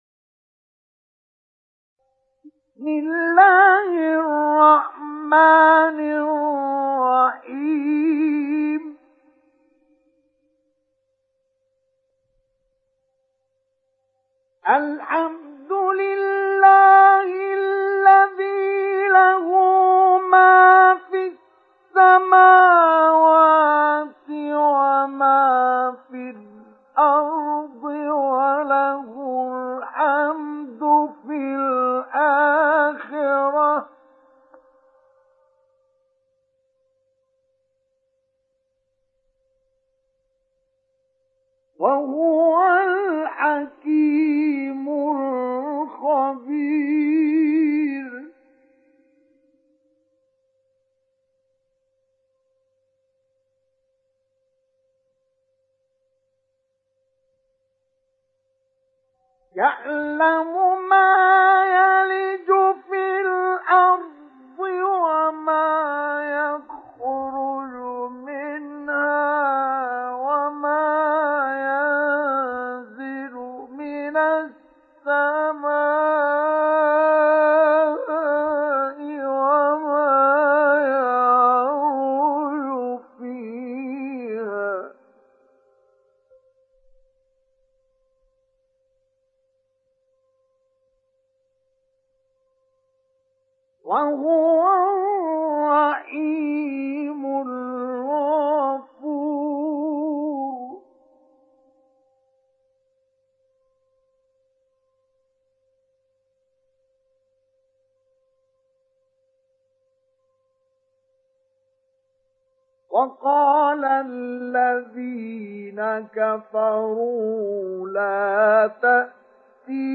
Download Surat Saba Mustafa Ismail Mujawwad